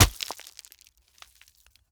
RockHitingGround_3.wav